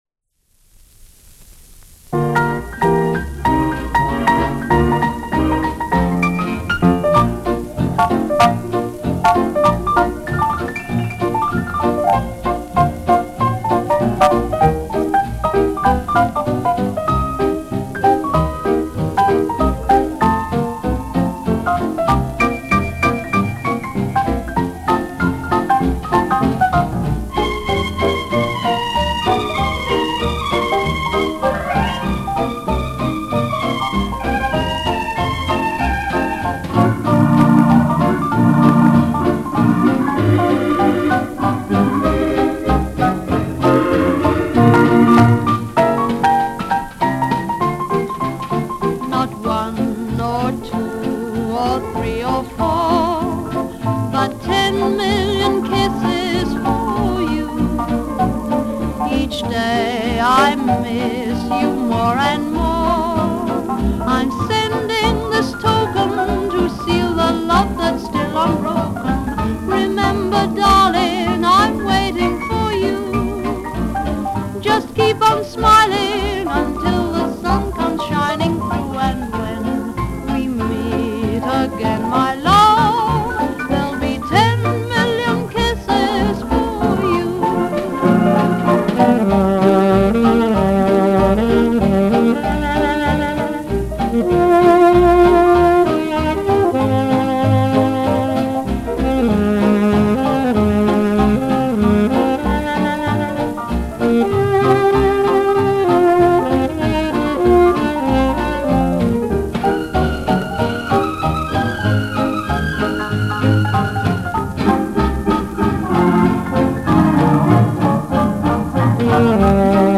500Hz Turnover